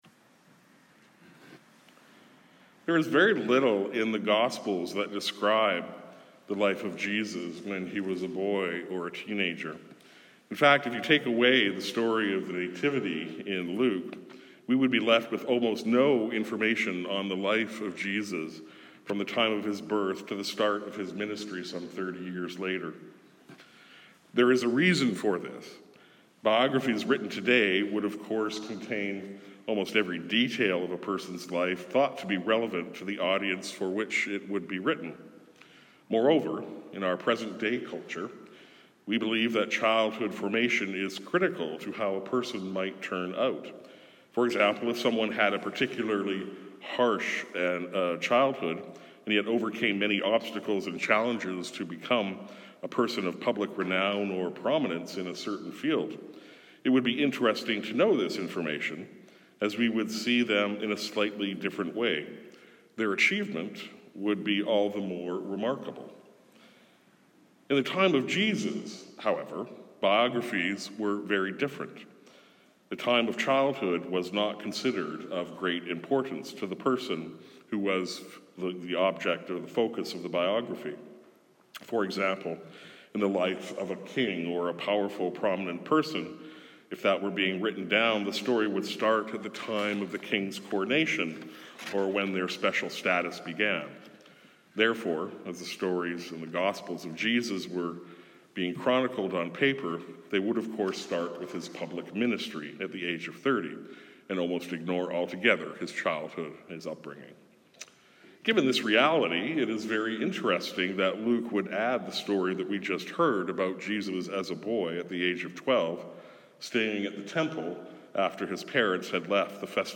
Sermons | St. John the Evangelist